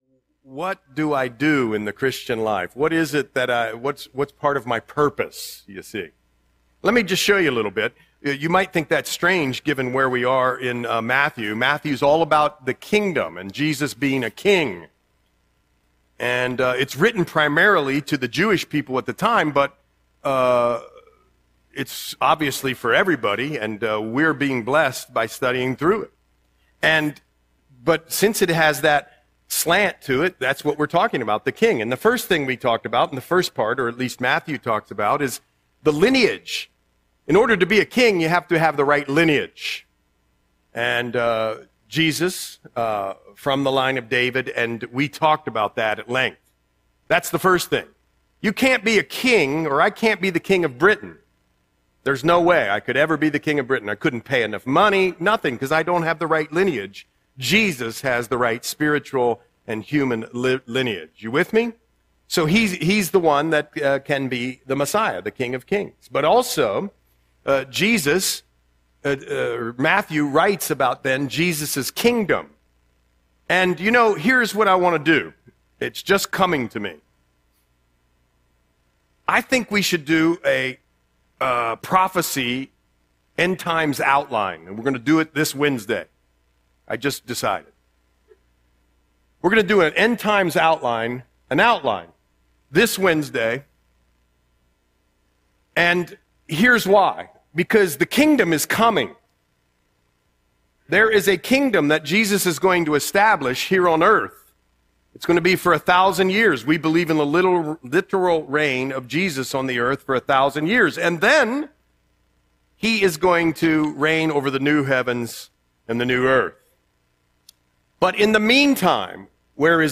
Audio Sermon - February 1, 2026